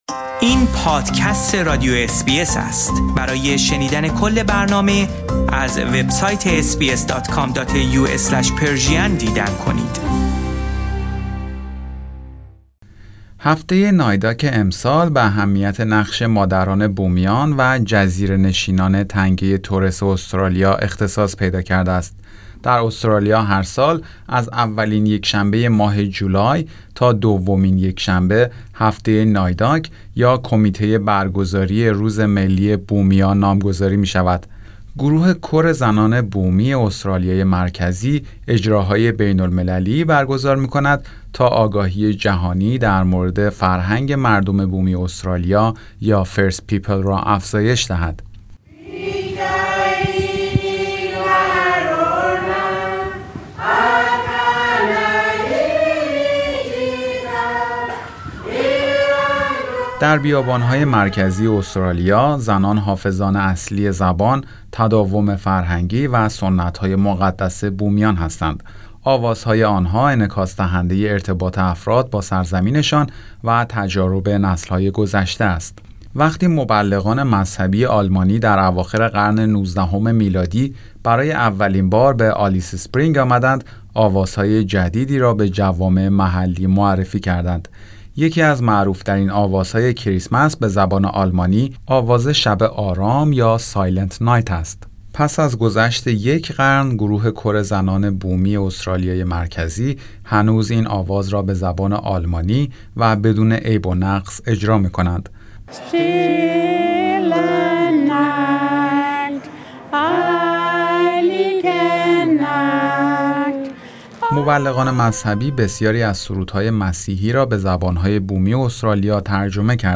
گروه کر زنان بومی استرالیای مرکزی Source: Brindle Films